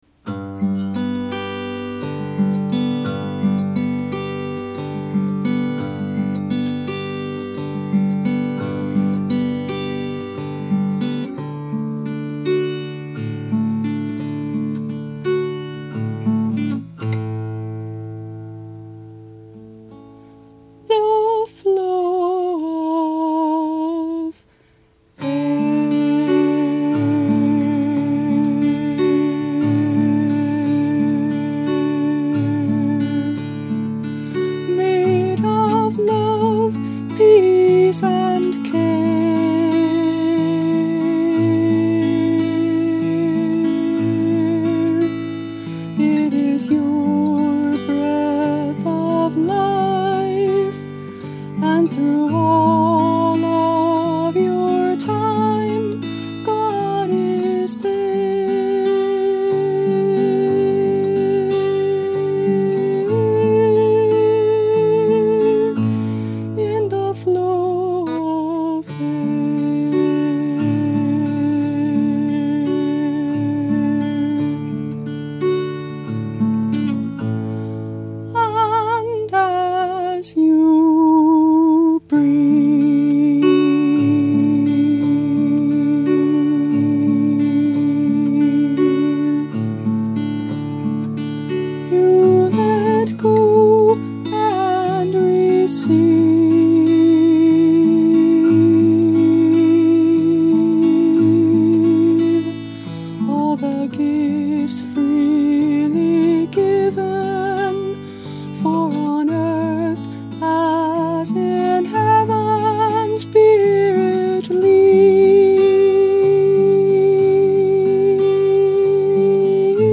Meditation Song
Instrument: Tempo – Seagull Excursion Folk Acoustic Guitar